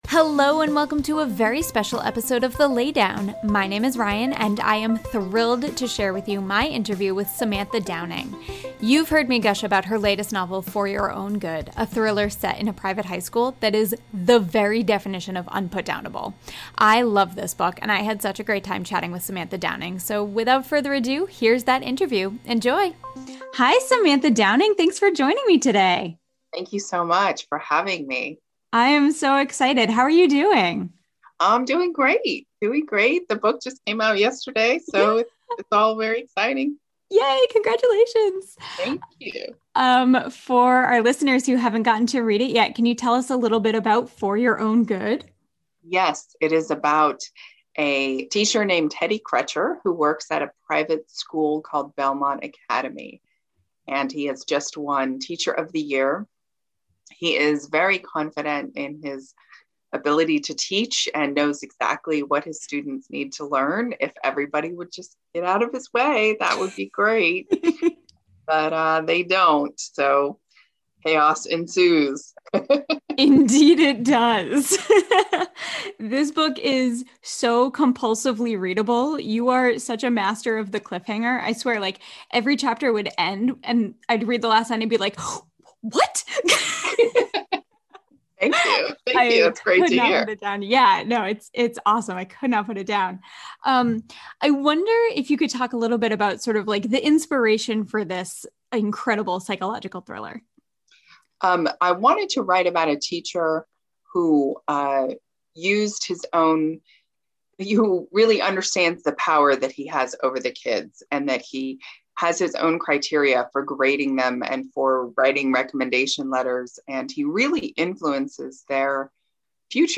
Samantha Downing Interview!